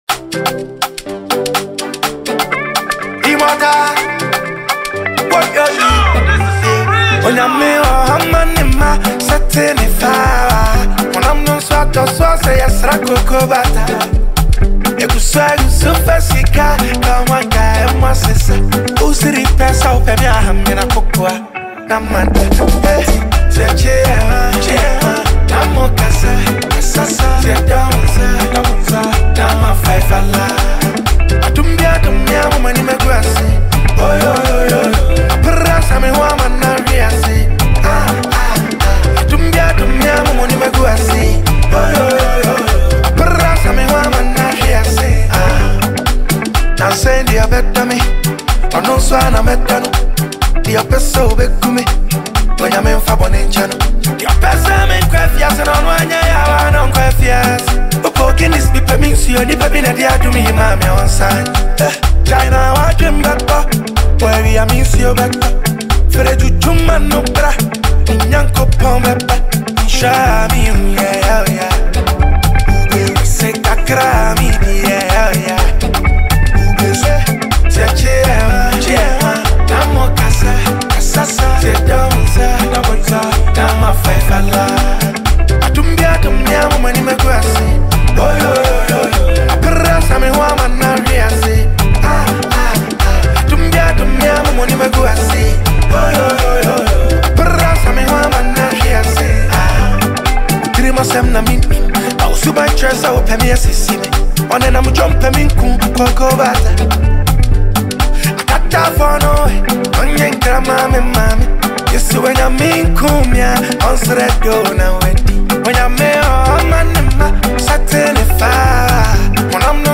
modern Afrobeat infused with conscious rap